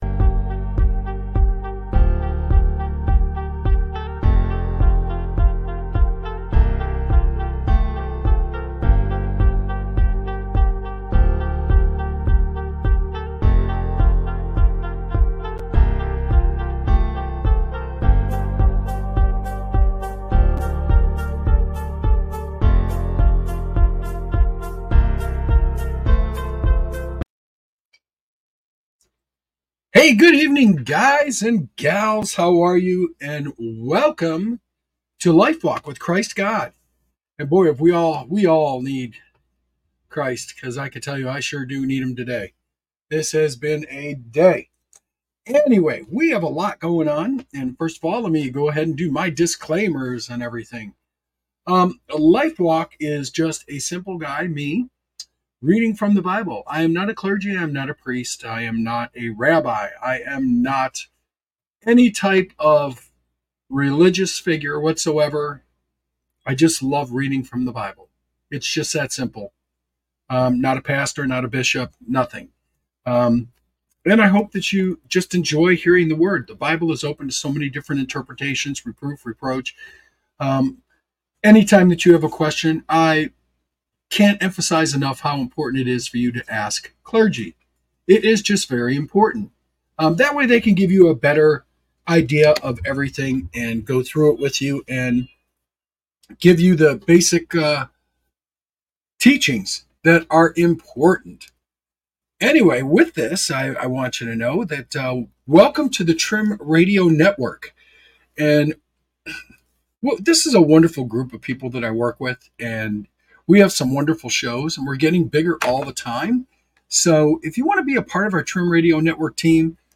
This show takes a fresh look at the Bible, offering weekly readings from the Bible.